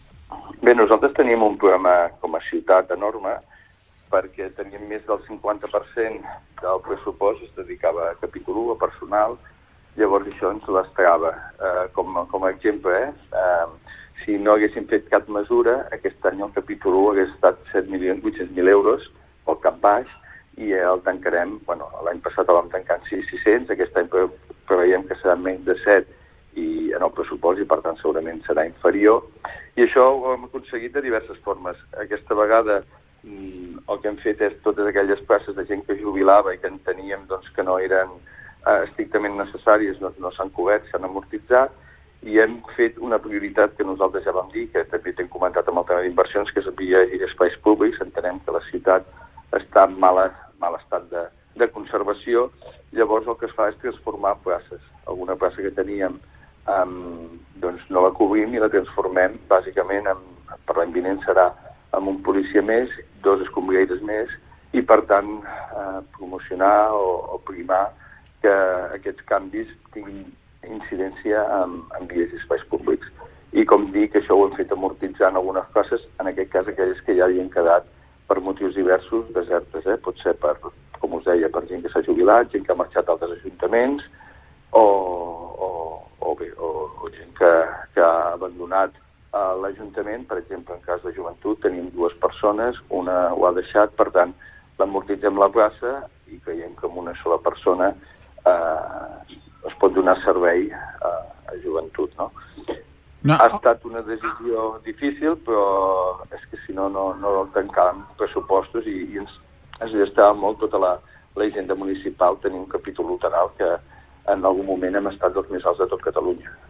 Supermatí - entrevistes
I per parlar dels pressupostos i d’aquestes inversions ens ha visitat al Supermatí l’alcalde del mateix municipi, Òscar Aparicio.